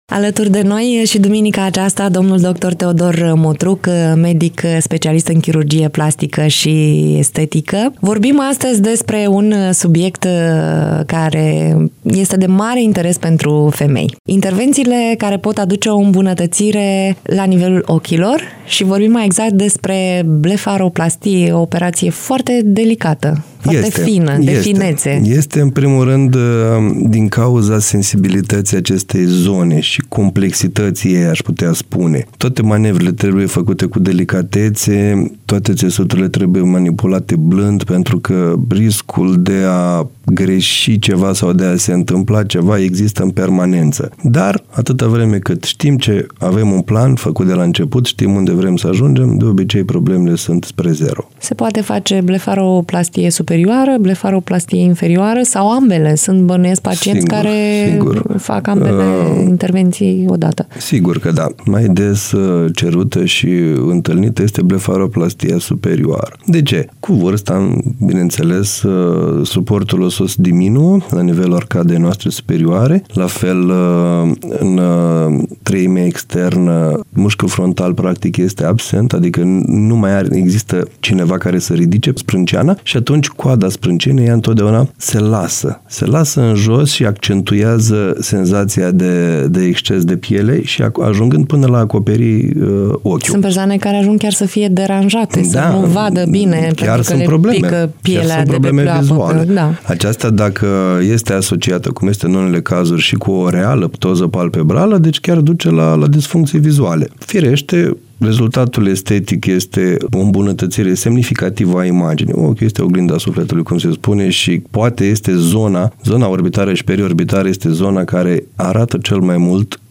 în direct la Bună Dimineața
interviu